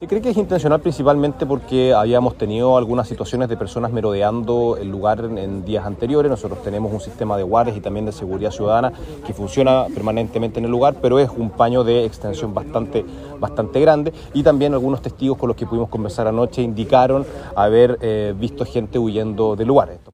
Por parte del alcalde de San Pedro de la Paz, Juan Pablo Spoerer, apunta a que el fuego “fue intencional, principalmente porque habíamos tenido algunas situaciones de personas merodeando el lugar los días anteriores”.